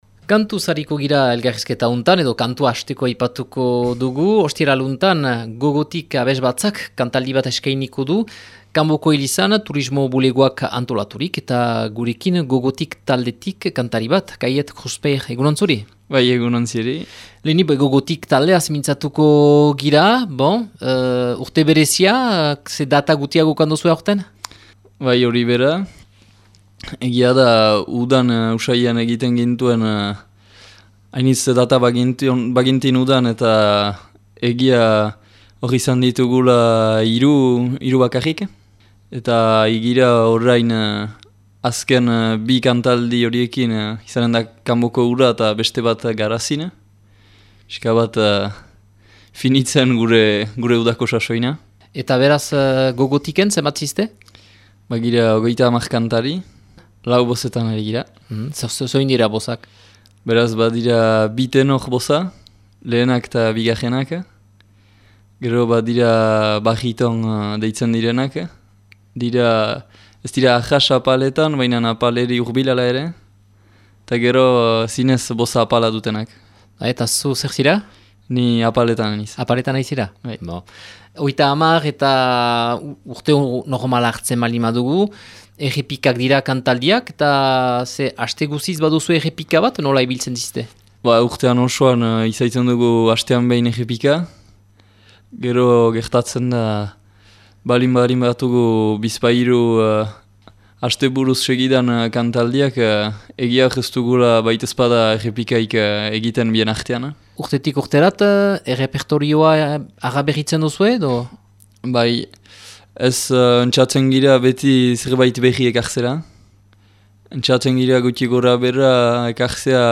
taldeko kide batekin mintzatu da